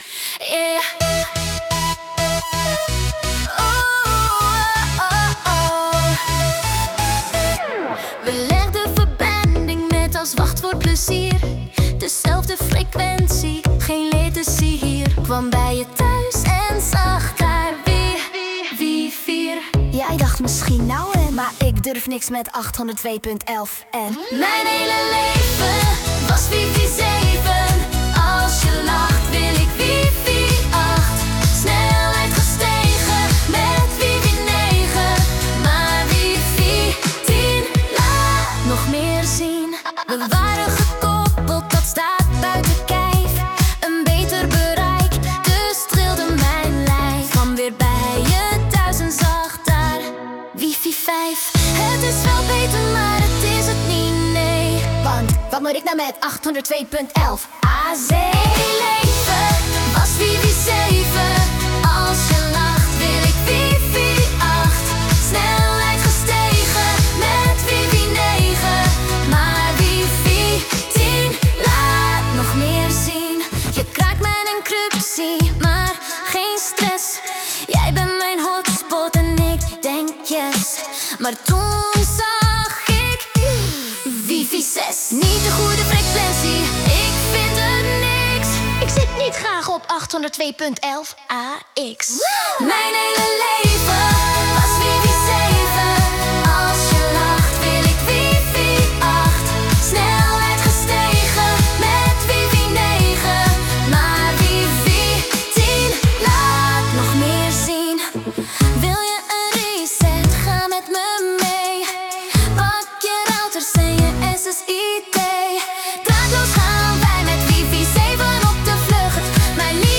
K-pop
Da's een aardig kpop nummer voor een cute concept.